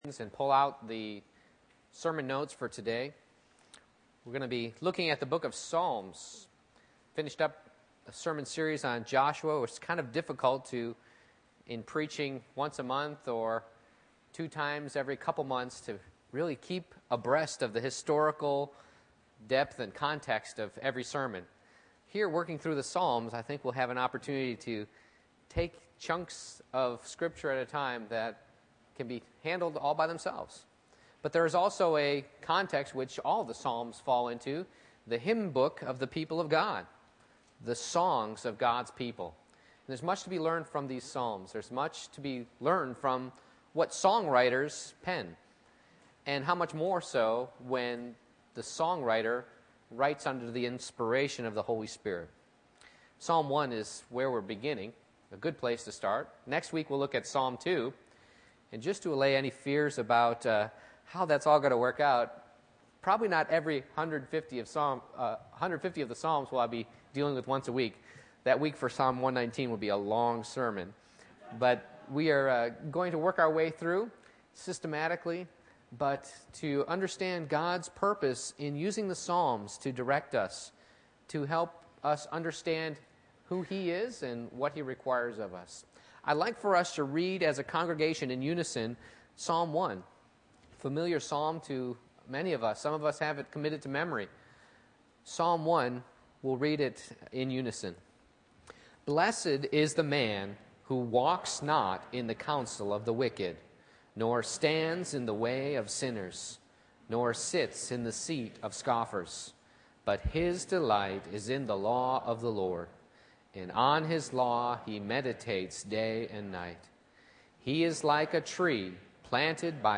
Psalm 1:1-6 Service Type: Morning Worship I. Two Ways to Live A. Ungodly 1.